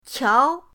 qiao2.mp3